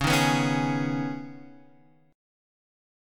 C#7sus2 chord